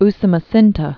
(sə-mə-sĭntə, -s-mä-sēntä)